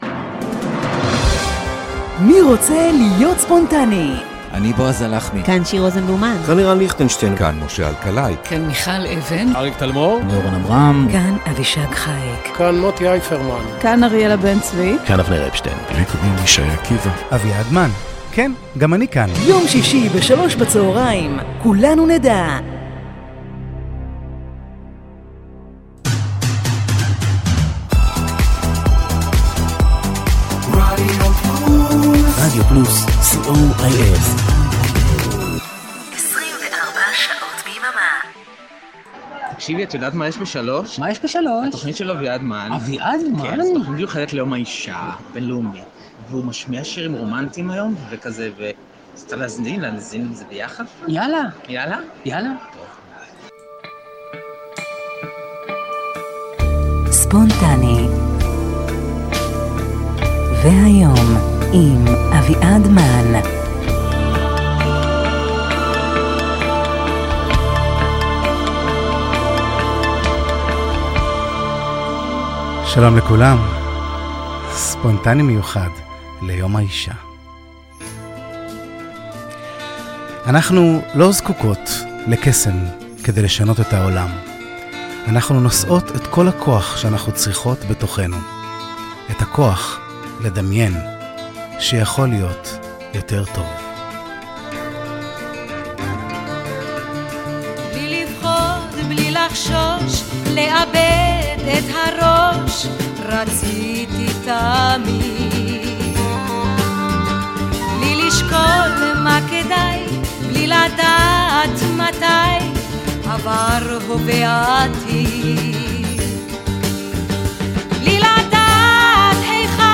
שעה של נשים חזקות ששרות, ונשים חזקות שאומרות דברים חזקים. באהבה למין החזק בעולם 🙂 יום האישה שמח!